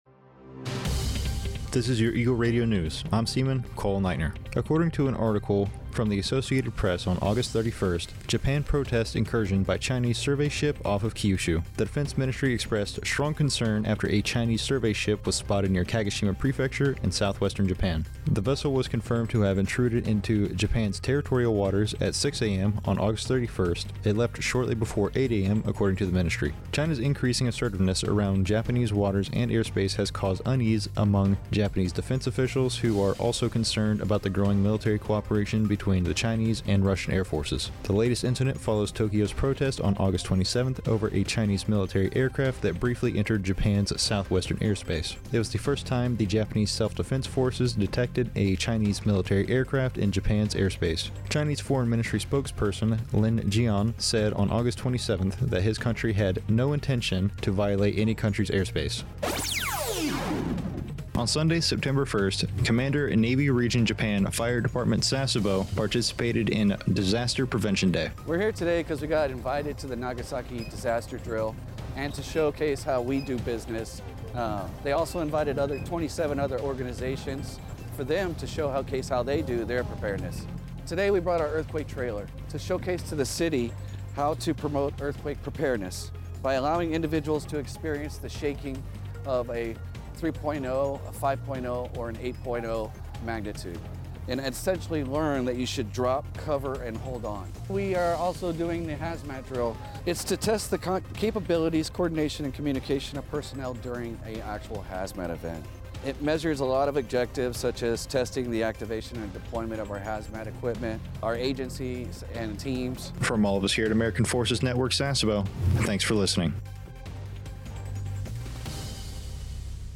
A TFNewscast for AFN Sasebo's radio about the Japanese protesting an incursion by Chinese survey ship off the coast of Kyushu and the CNRJ Fire Department Sasebo's participation in "Disaster Prevention Day".